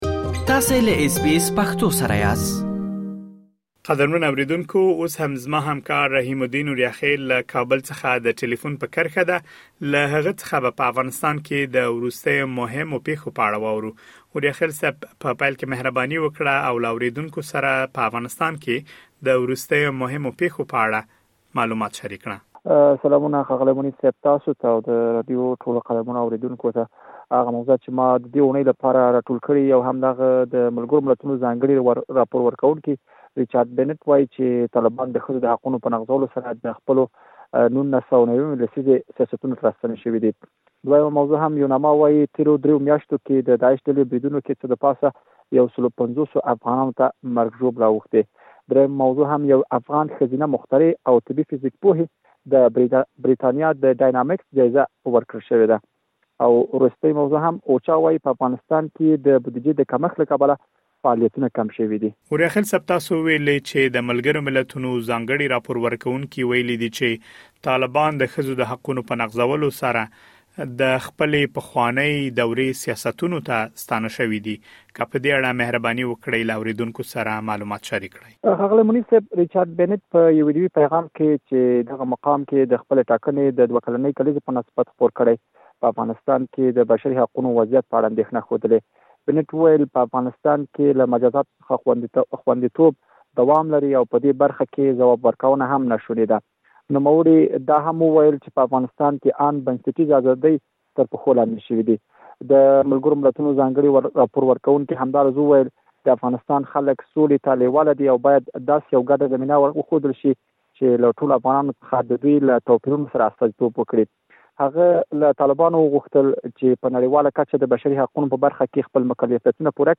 په داسې حال کې چې په افغانستان کې نږدې ۲۴ میلیونه کسان بېړنیو مرستو ته اړتیا لري، د ملګرو ملتونو د بشري مرستو د همغږۍ دفتر (اوچا) وايي، په افغانستان کې یې فعالیتونه کم شوي. د افغانستان د مهمو پېښو په اړه وروستي معلومات په مرکې کې اورېدلی شئ.